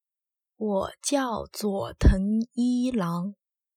wǒ jiào zuǒ téng yī láng
ウォ ズゥォトン イーラン。